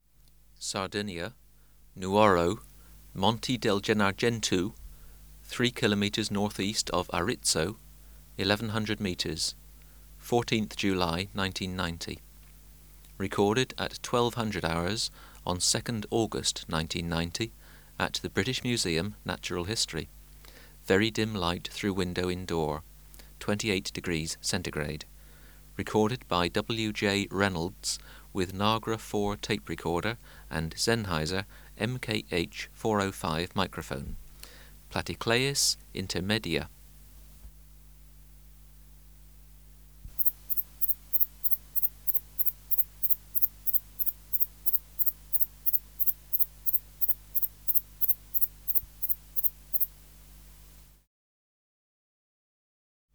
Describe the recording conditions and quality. Recording Location: BMNH Acoustic Laboratory Substrate/Cage: Large recording cage Microphone & Power Supply: Sennheiser MKH 405 Filter: Low pass, 24 dB per octave corner frequency 20 Hz